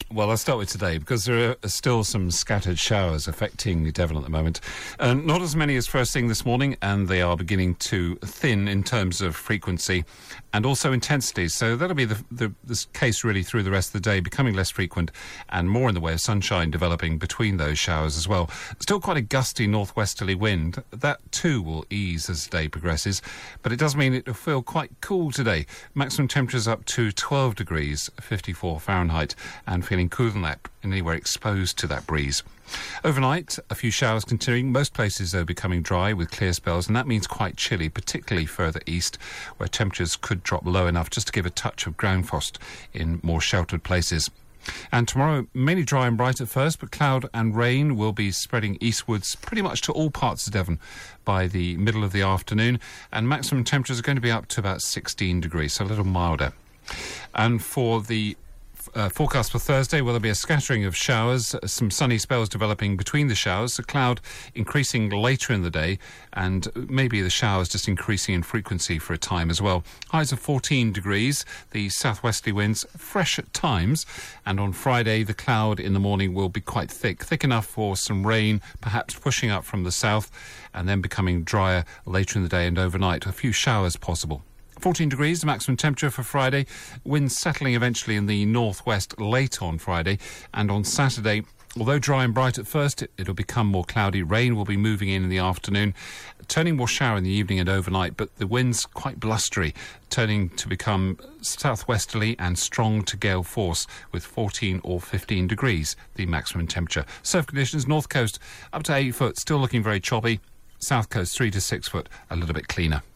5 day forecast for Devon from 8.35AM on 29 October